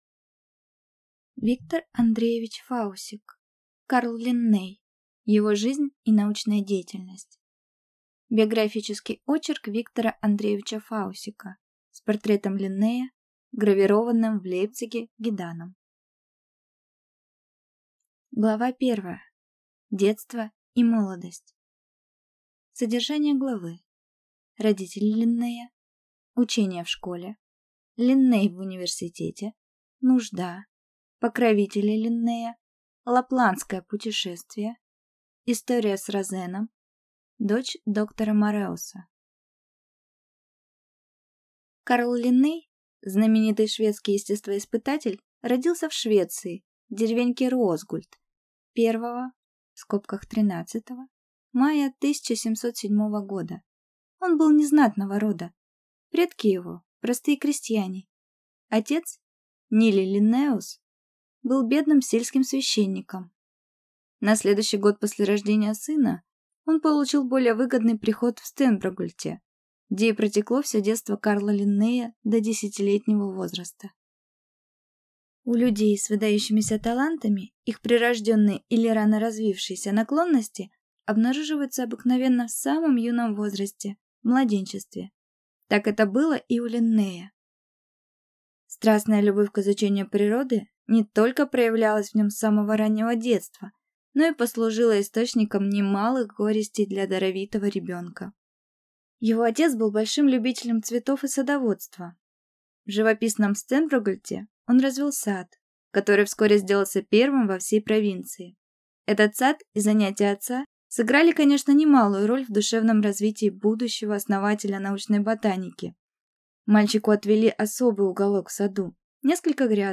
Аудиокнига Карл Линней. Его жизнь и научная деятельность | Библиотека аудиокниг